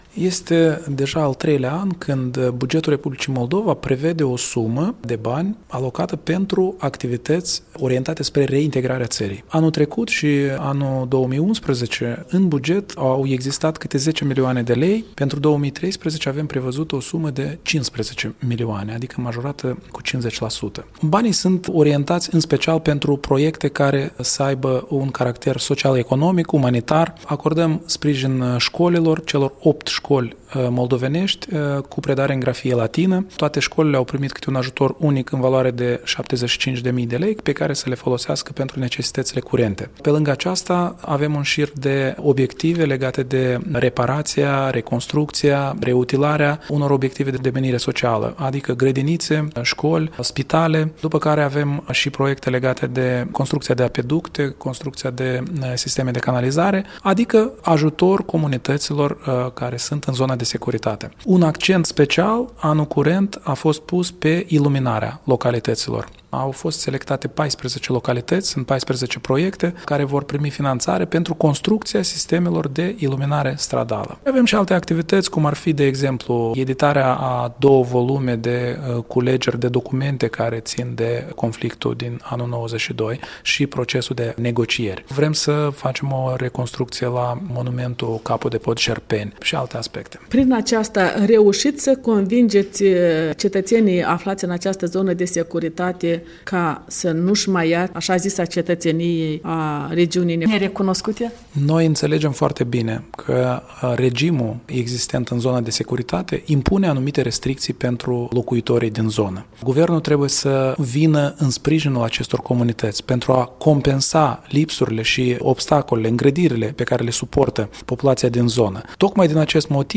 Interviu cu Eugen Carpov